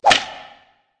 traphit_3.ogg